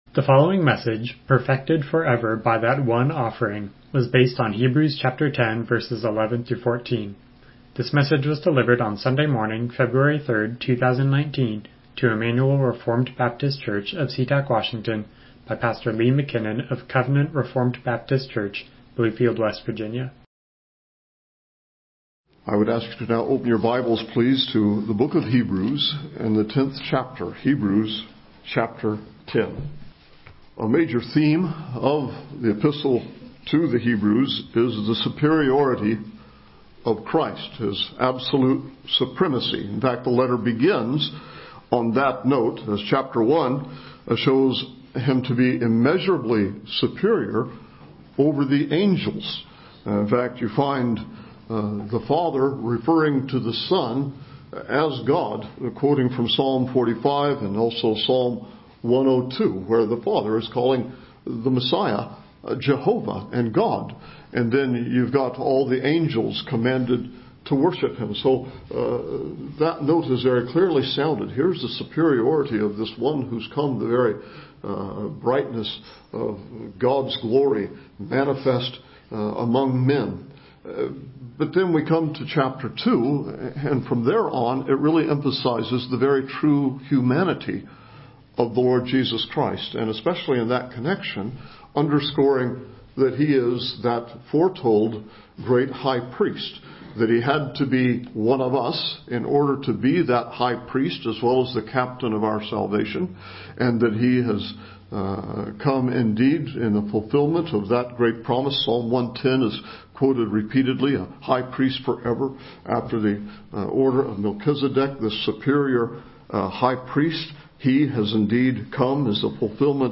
Miscellaneous Passage: Hebrews 10:11-14 Service Type: Morning Worship « Benefits of Numbering Our Days Jesus